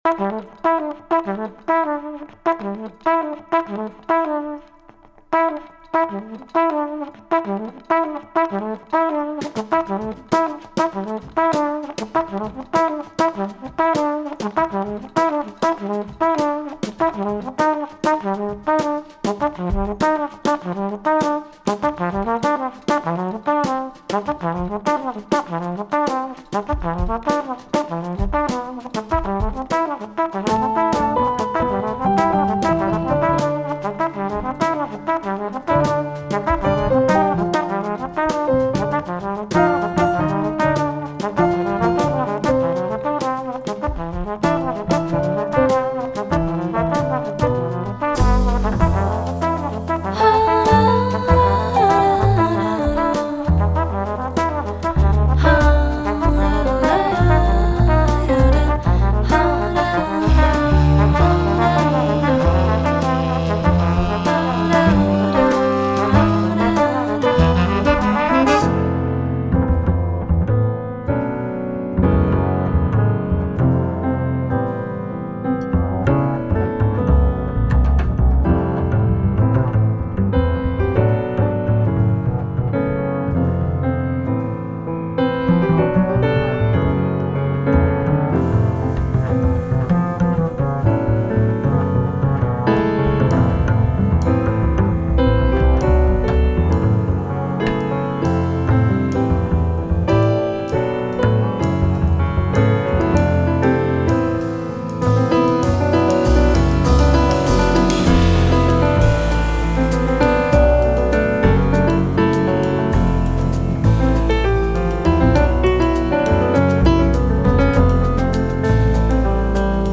trompette
saxophone
piano),  (batterie
contrebasse